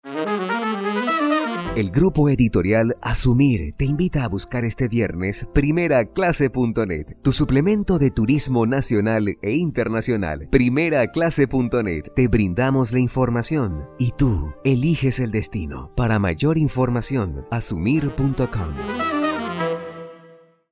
Sprechprobe: Sonstiges (Muttersprache):